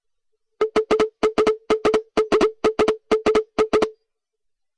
Und so hört es sich an, wenn das Mobiltelefon mit der Basis
Kontakt aufnimmt.
Ton_Mobiltelefon.mp3